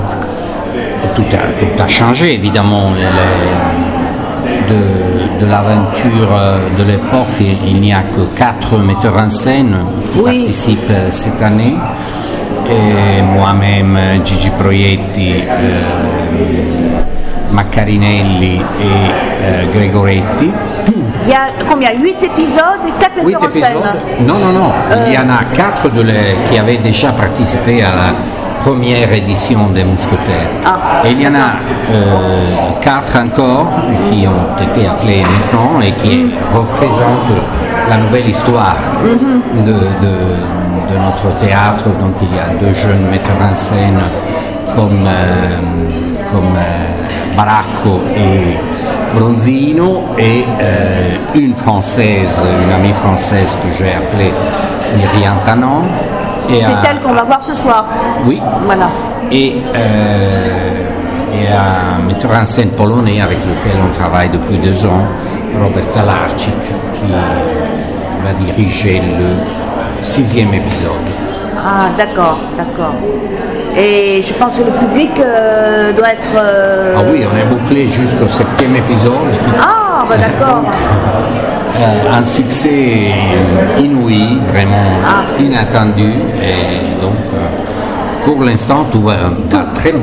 Interview expresse